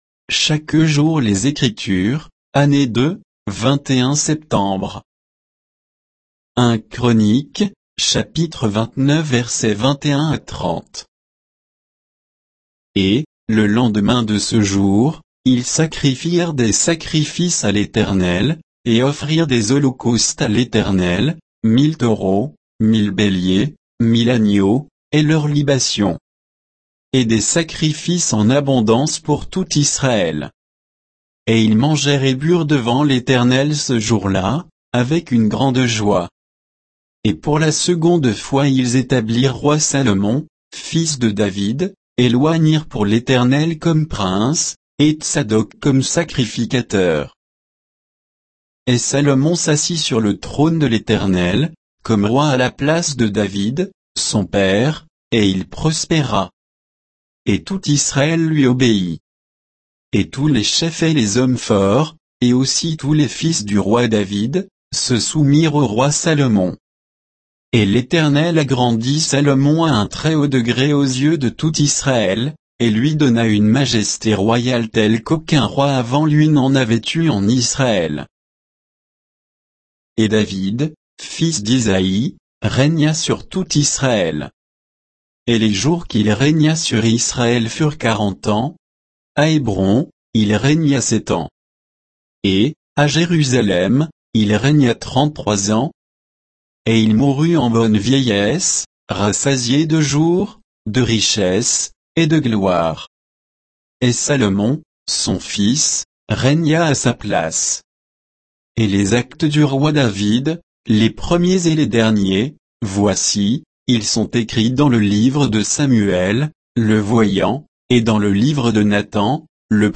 Méditation quoditienne de Chaque jour les Écritures sur 1 Chroniques 29